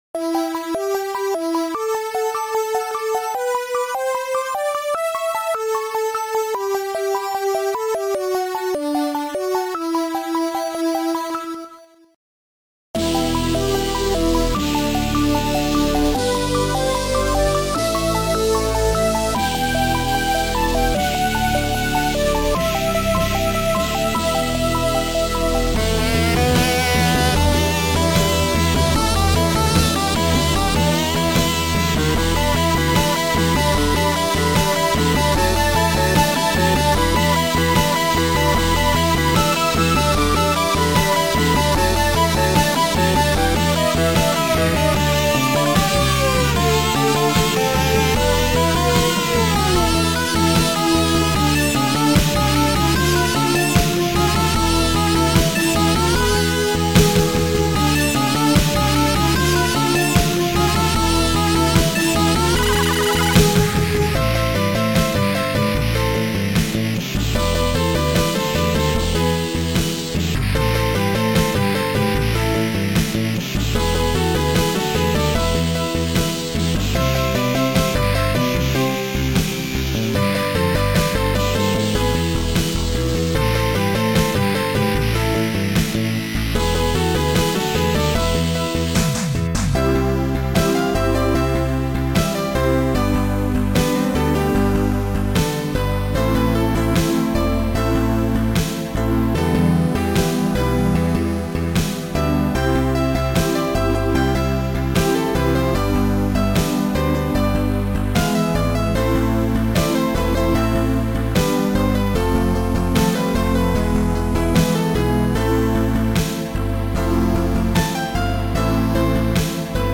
Sound Format: Noisetracker/Protracker